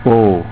Long Vowels
oo as in coat, boat